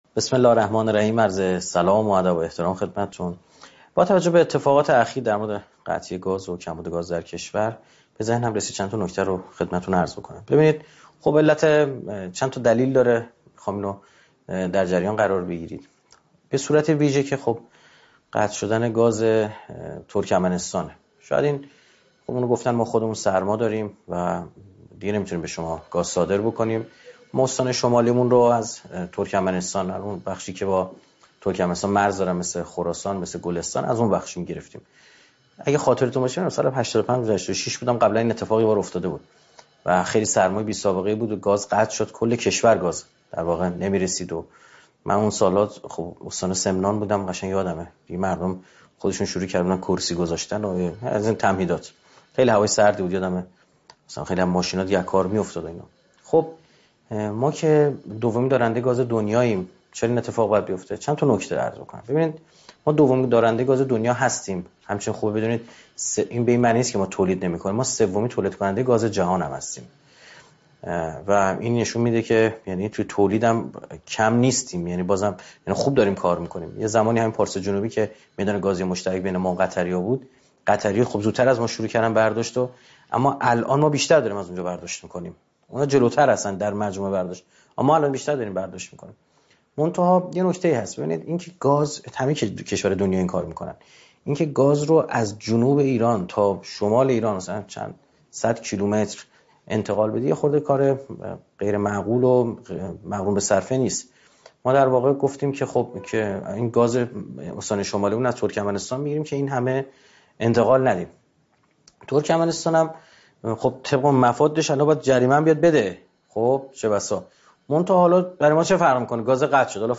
مکان : تهران